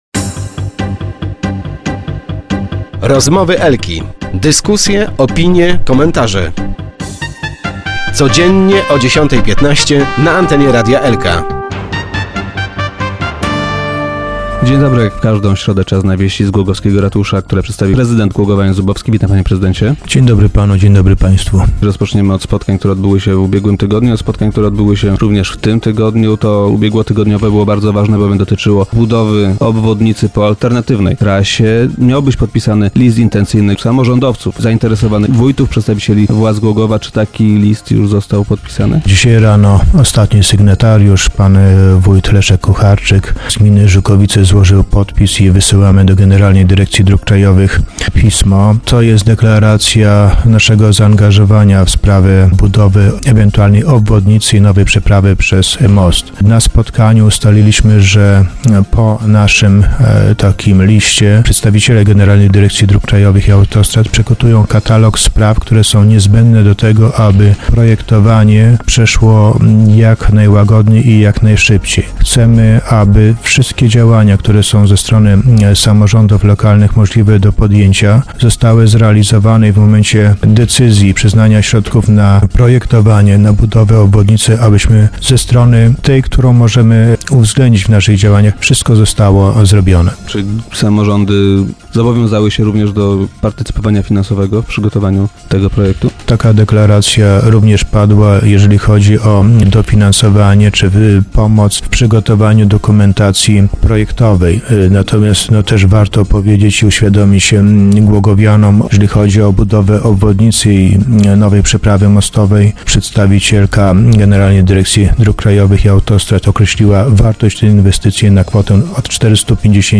Po otrzymaniu tego listu przedstawiciele GDDKiA przygotują katalog spraw, których załatwienie z naszej strony jest niezbędne, by projektowanie przebiegało szybko i sprawnie - mówił dziś na antenie Radia Elka prezydent Jan Zubowski.